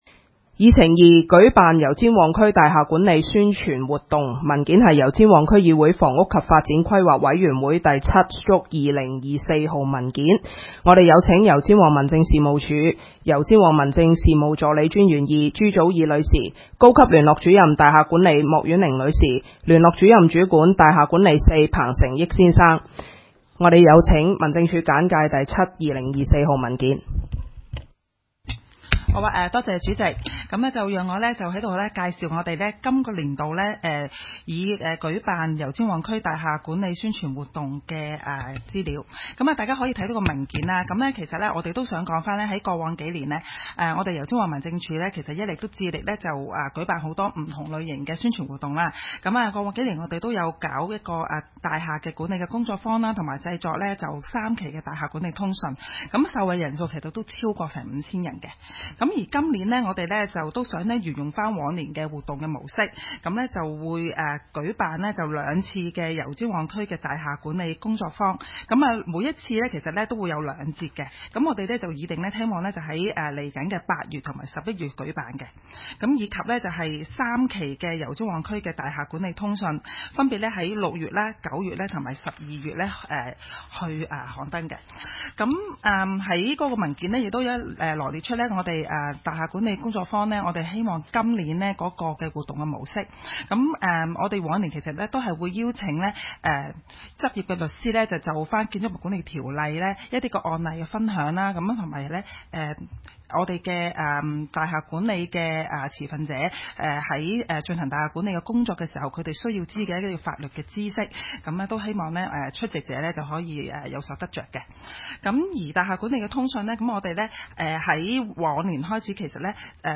油尖旺區議會 - 委員會會議的錄音記錄
會議的錄音記錄